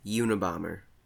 YOO-nə-bom-ər), was an American mathematician and domestic terrorist.[1][2] He was a mathematics prodigy, but abandoned his academic career in 1969 to pursue a reclusive primitive lifestyle and lone wolf terrorism campaign to further his political agenda.
En-us-Unabomber.ogg.mp3